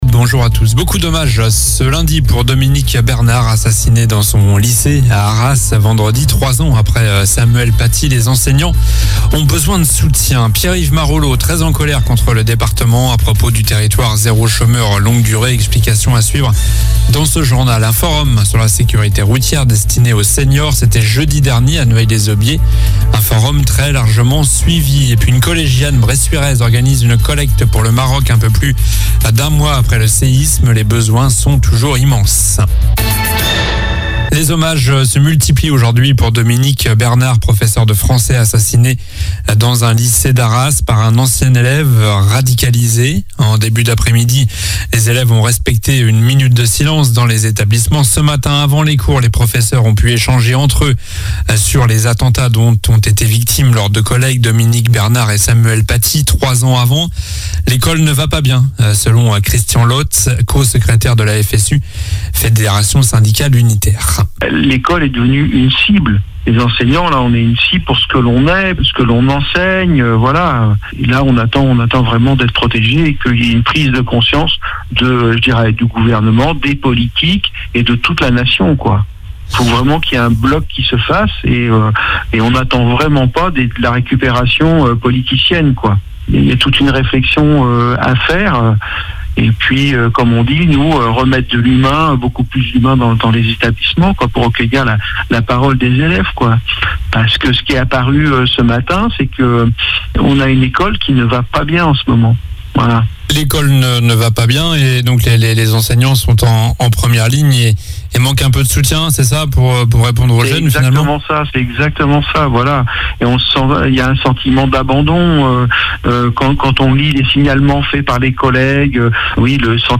JOURNAL DU LUNDI 16 OCTOBRE ( soir )